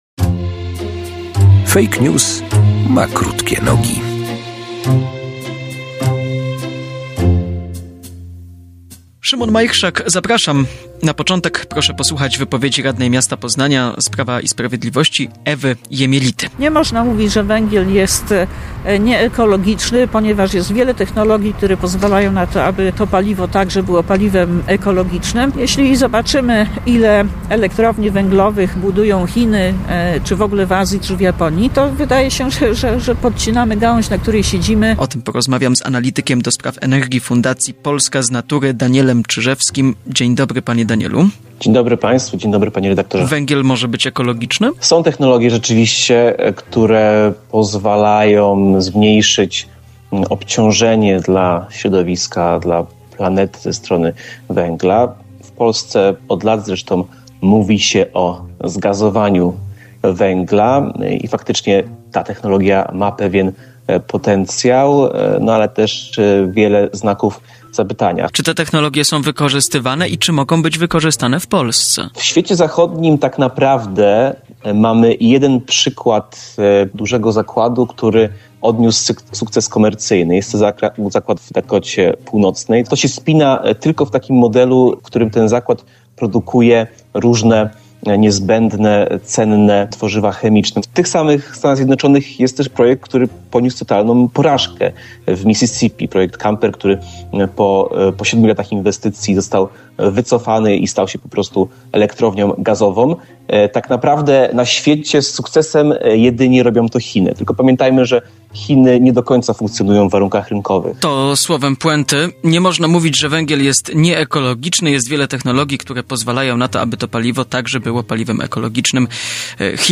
Nie można mówić, że węgiel jest nieekologiczny - ile prawdy jest w tym stwierdzeniu? Zapraszam na rozmowę